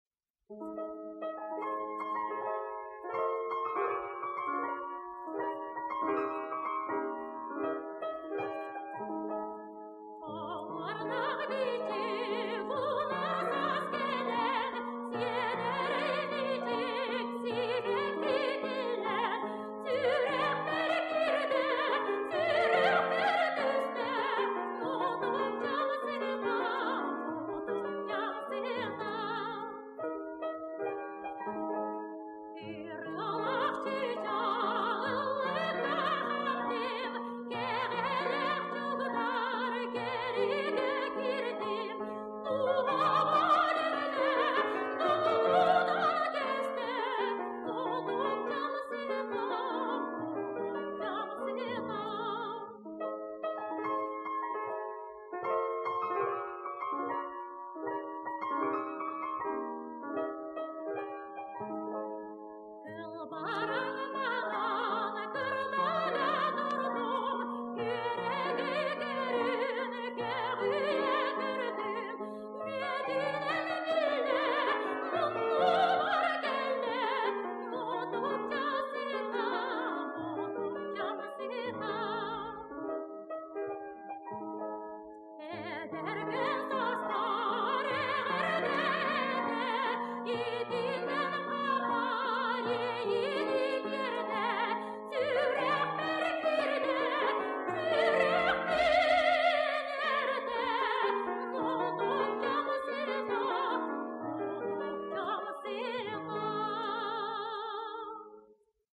Фортепиано